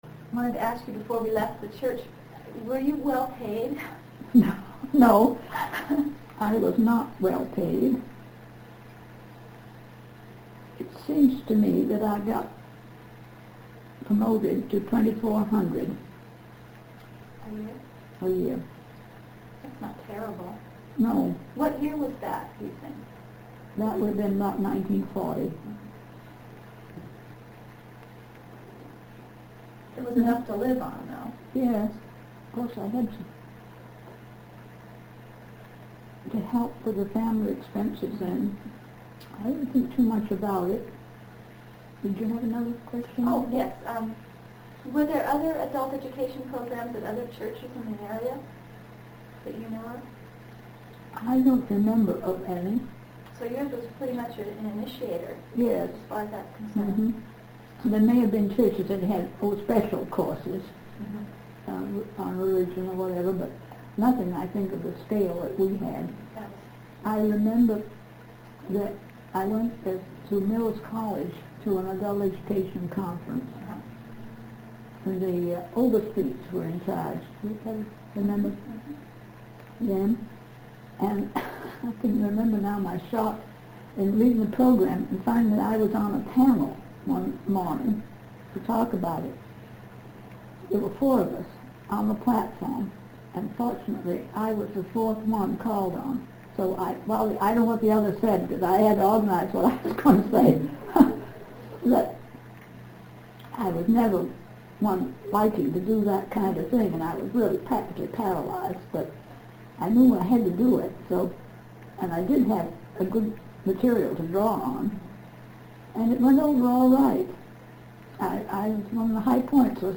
INTERVIEW DESCRIPTION - Recorded in her living quarters at Pilgrim's Place, the audio quality of this interview is good. 3/1/1977